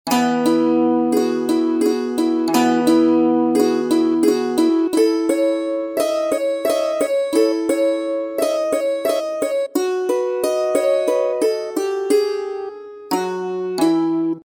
Santoor
The santoor is a dulcimer that originated in Persia.
The santoor consists of 15 clusters of strings that are struck with small mallets or kalams .
santoor.mp3